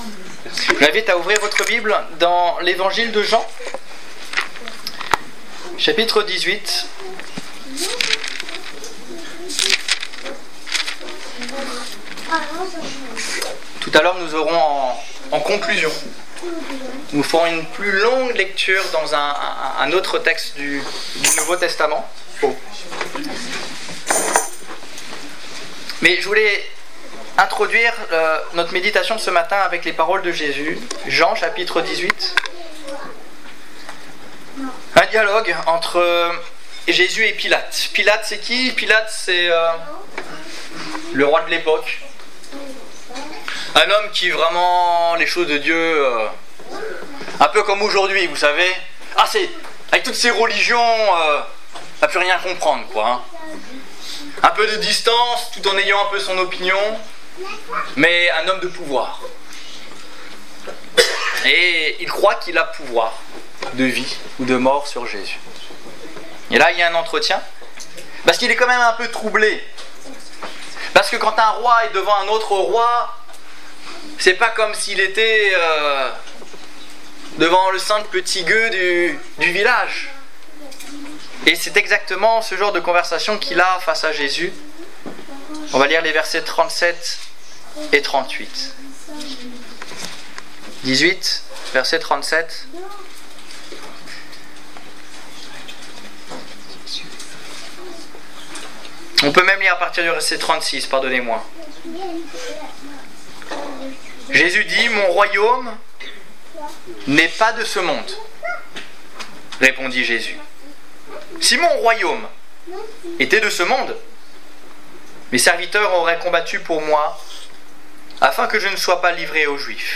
La vérité Détails Prédications - liste complète Culte du 25 septembre 2016 Ecoutez l'enregistrement de ce message à l'aide du lecteur